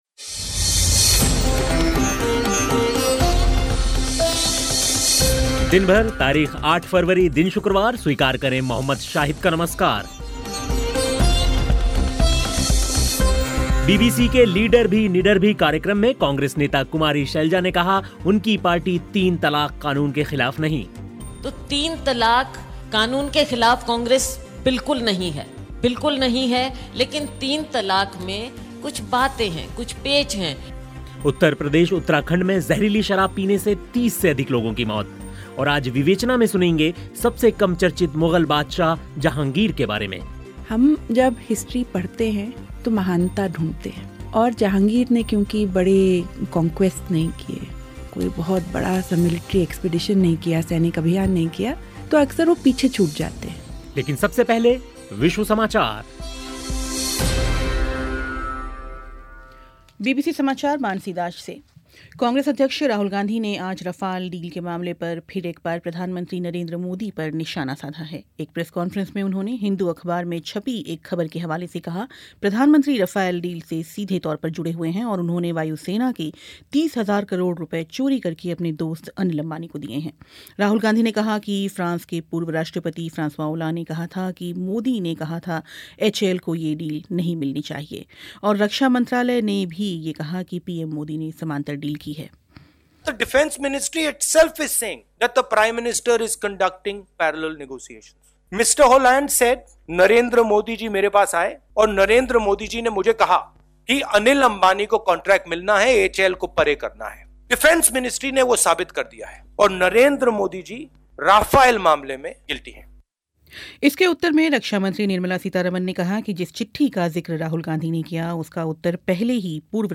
विवेचना में सबसे कम चर्चित मुग़ल बादशाह जहांगीर के बारे में सुनाएंगे. खेल की भी ख़बरें होंगी लेकिन सबसे पहले विश्व समाचार सुनिए.